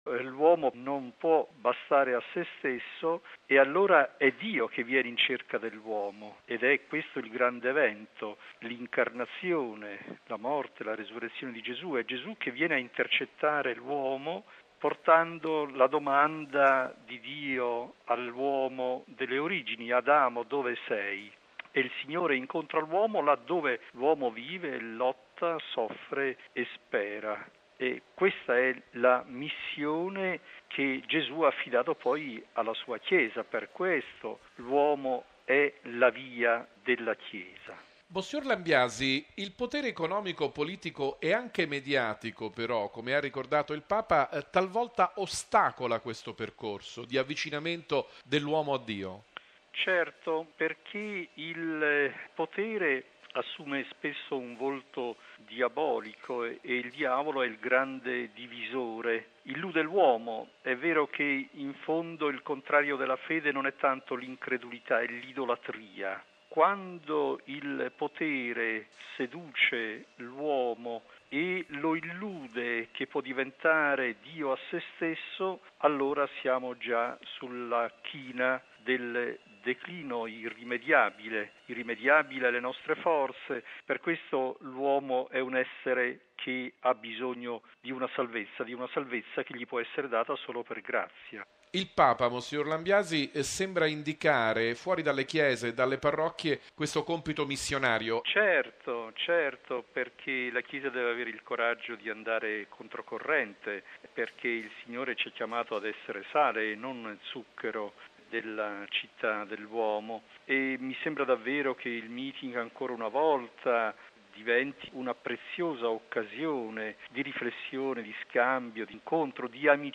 mons. Francesco Lambiasi, vescovo di Rimini